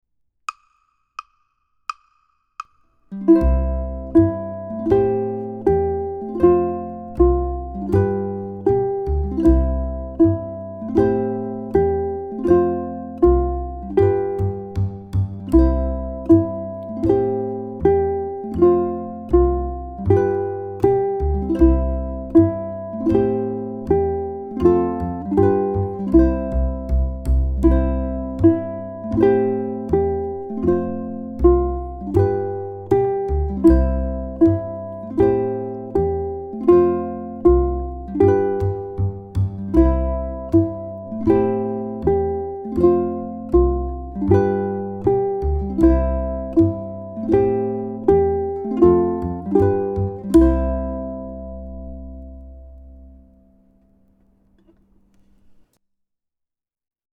ʻukulele
Walkin' is in duple meter (two-beat meter).
Most chords in Walkin' change every two beats.